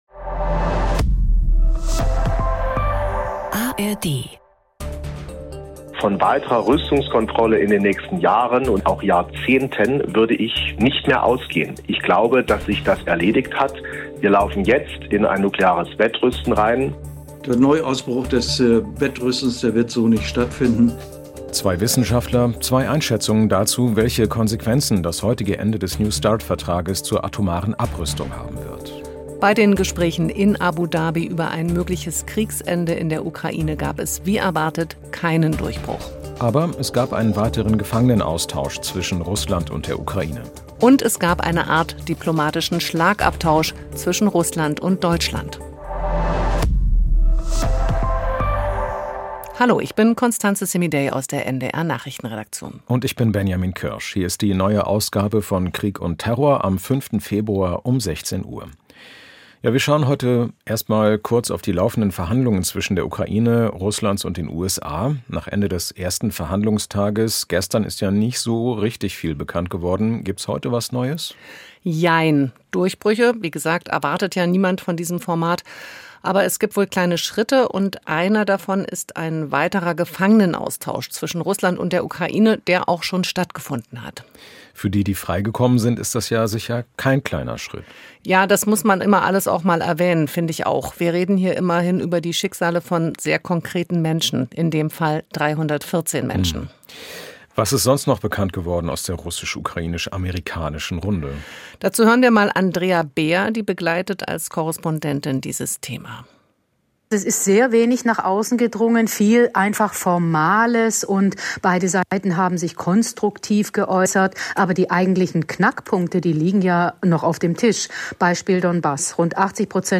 Der NEW START Abrüstungsvertrag ist heute ausgelaufen - gibt es jetzt ein neues atomares Wettrüsten? Zwei Experten geben ihre Einschätzung.